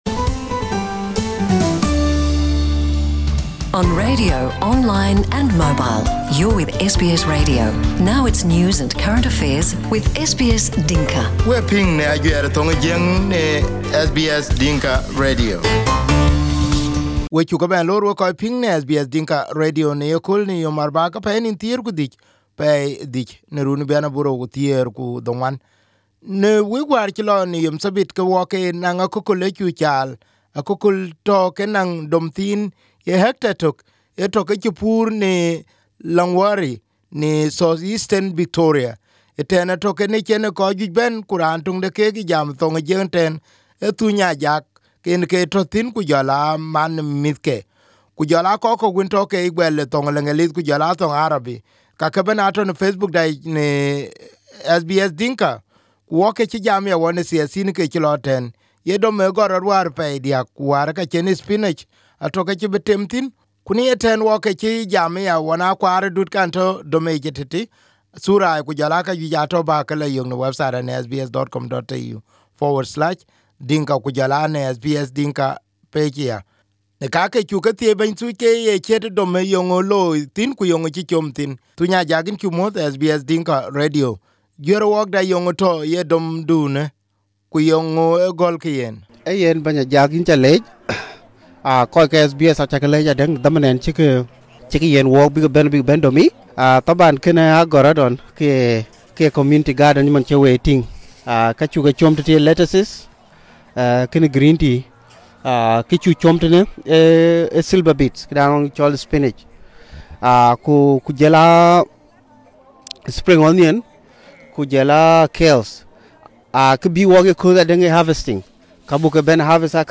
Interview
during the United African Farm Harvest Festival in Longwarry. The United African Farm brought together various African communities, politicians and the local farmers together on Saturday during the harvest festival.